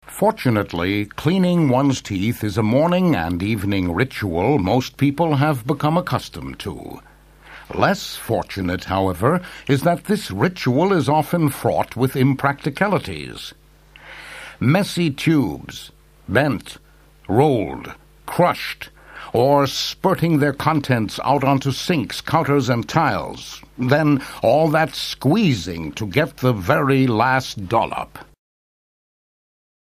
Amerikanischer Sprecher (transatlantisch) mit verschiedenen Stimmlagen und Akzente, TV Moderator, Schauspieler, Werbesprecher, Film-Vertonungen, Übersetzungen
mid-atlantic
Sprechprobe: Industrie (Muttersprache):